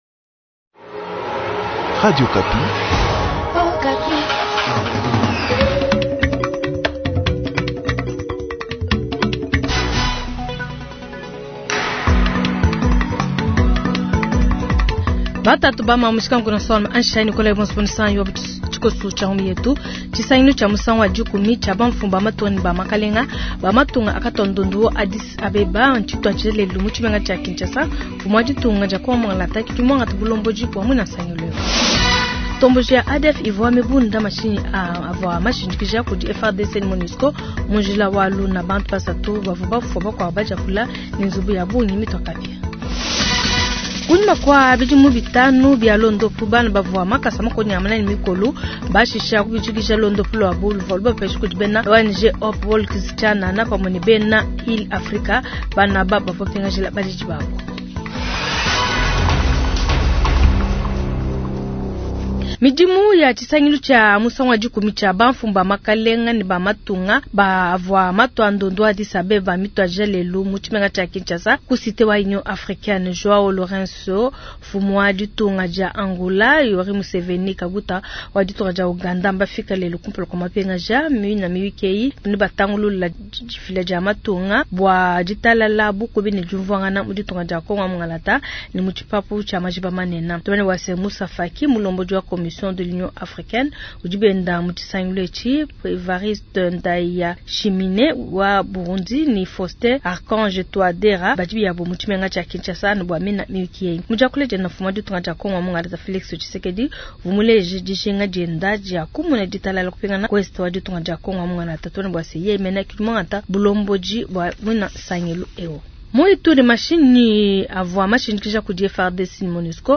Journal soir
Kinshasa : 10 eme sommet, extrait du discours du président de la commission de l’union africaine